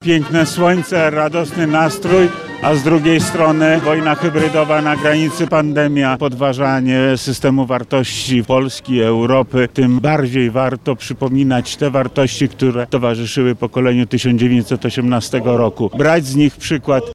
Na Placu Litewskim w Lublinie zakończyły się lubelskie uroczystości Narodowego Święta Niepodległości.